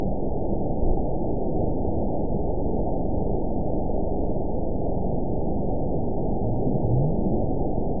event 920313 date 03/15/24 time 09:04:46 GMT (1 year, 1 month ago) score 8.23 location TSS-AB01 detected by nrw target species NRW annotations +NRW Spectrogram: Frequency (kHz) vs. Time (s) audio not available .wav